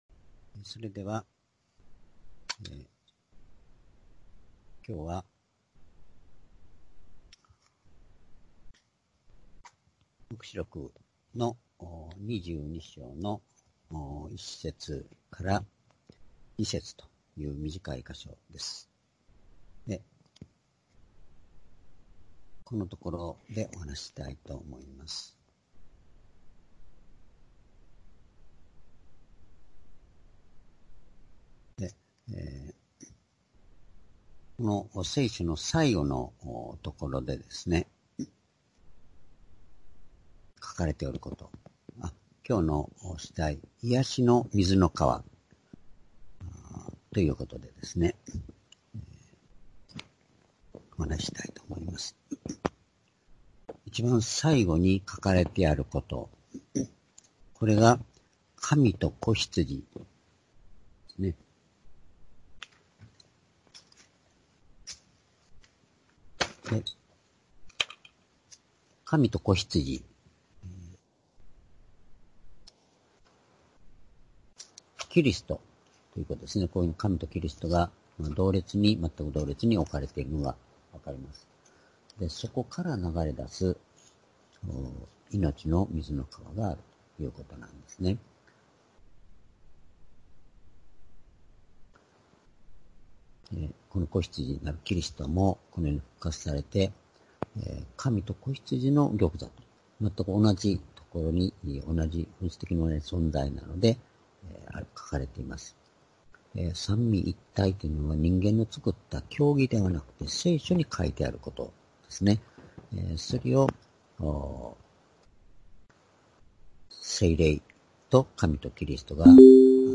主日礼拝日時 2020年3月1日（主日） 聖書講話箇所 「癒しの水の河」 黙示録22章1節～2節 ※視聴できない場合は をクリックしてください。